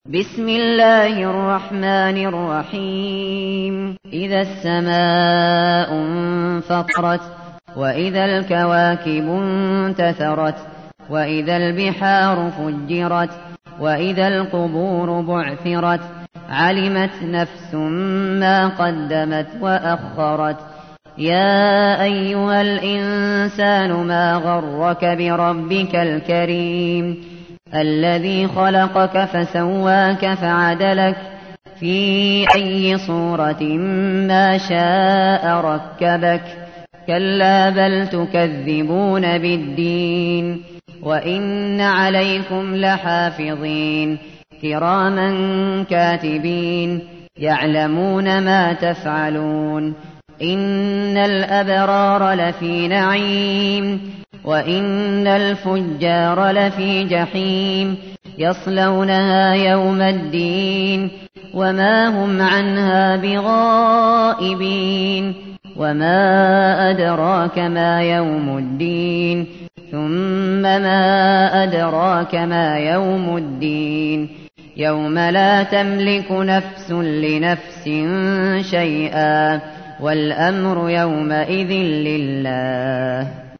تحميل : 82. سورة الانفطار / القارئ الشاطري / القرآن الكريم / موقع يا حسين